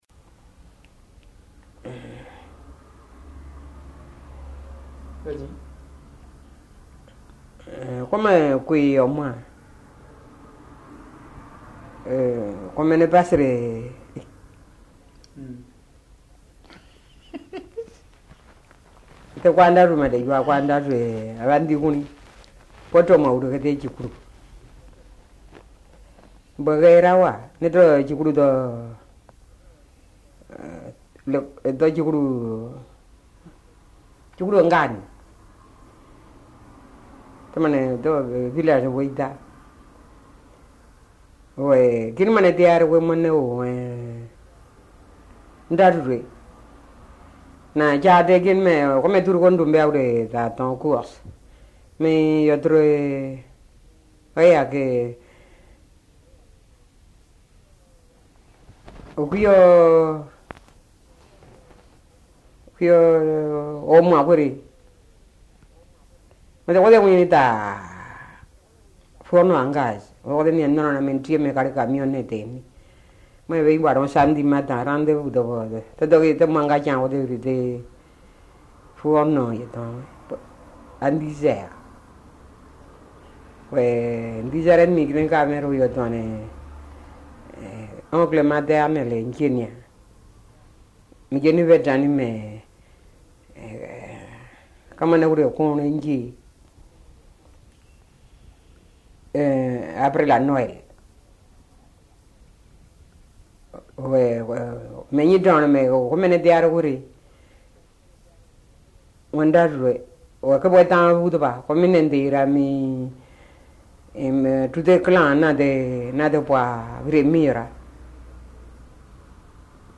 Documents joints Dialogue leçon 14 ( MP3 - 2.8 Mio ) Un message, un commentaire ?